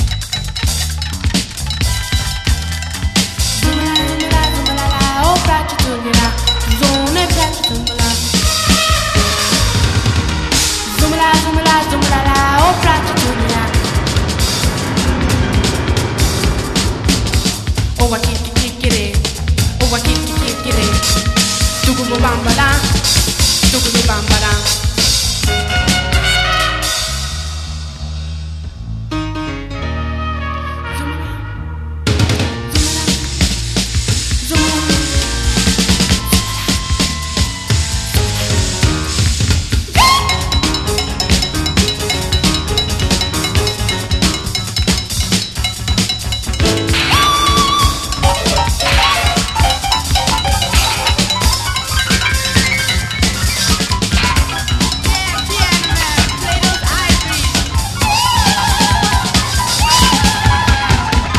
エキゾでトロピカルでファンキーでヒップ・ホップでコミカルな名盤！